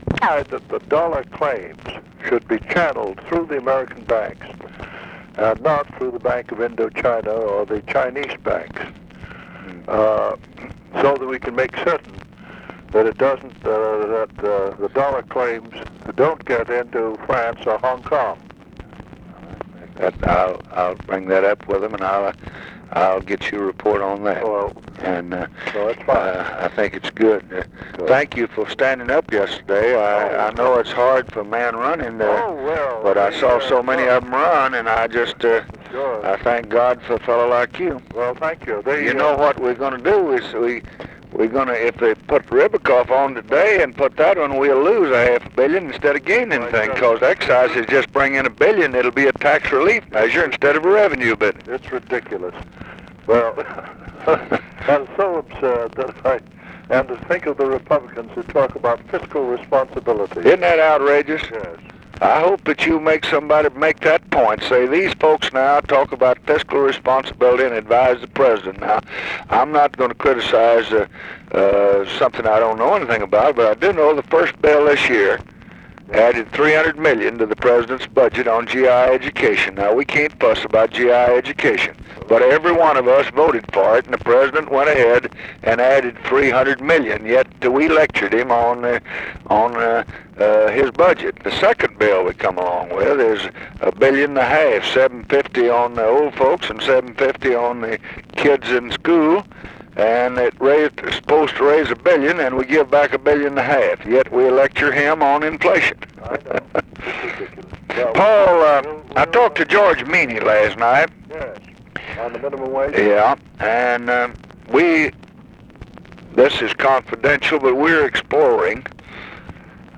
Conversation with PAUL DOUGLAS, March 9, 1966
Secret White House Tapes